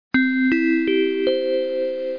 bell dang ding dong ring school sound effect free sound royalty free Sound Effects